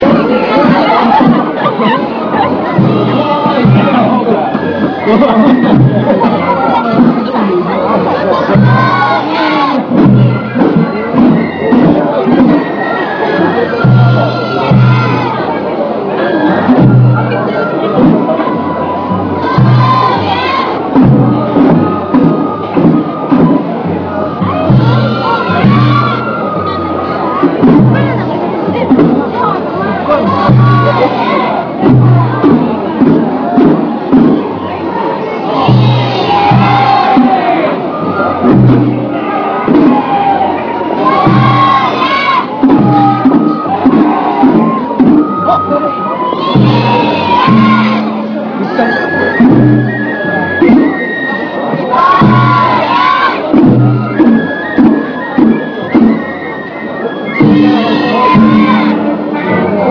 囃子方は、概ね　笛は２ 、３人、鉦ひとつ、前後に３個づつで計６個の締め太鼓と、したやまの天井から吊され ている胴の薄いオオダイがひとつです。能管笛が主流のようで、能楽に近い山車囃子と 思えました。夜山（よやま）と呼ばれる４日の夜の"やま"の巡行時に録音した